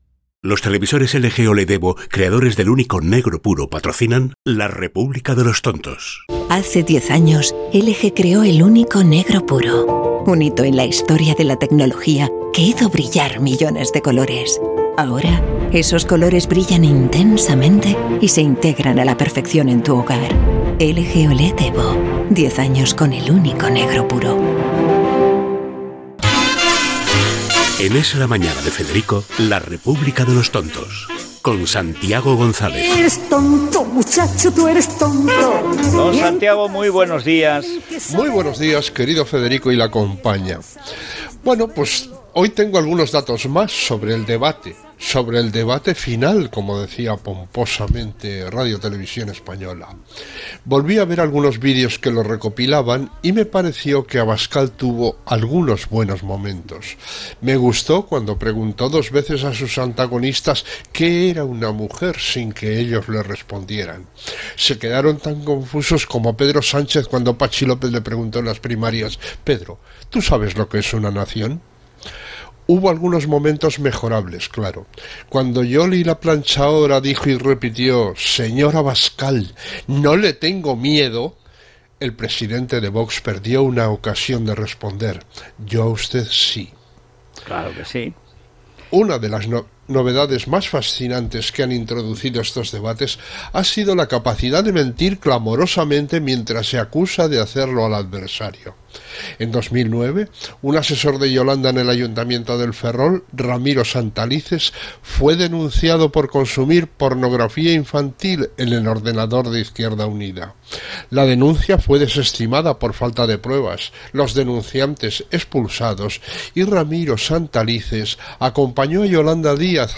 publicitat, careta de l'espai, comentari del debat a les eleccions generals espanyoles fet a TVE
Info-entreteniment